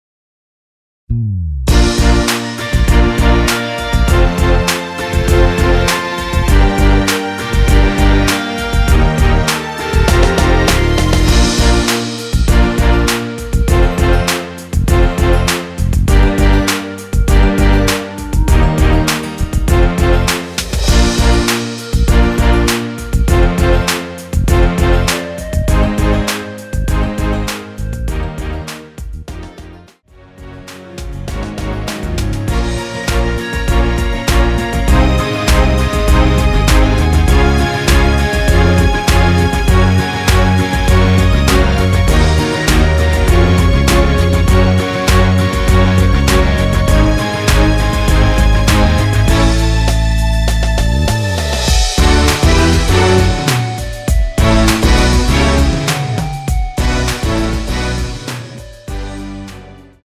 원키에서(-1)내린 멜로디 포함된 MR입니다.(미리듣기 참조)
Bb
앞부분30초, 뒷부분30초씩 편집해서 올려 드리고 있습니다.
중간에 음이 끈어지고 다시 나오는 이유는